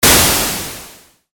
アタック 010
ダーザー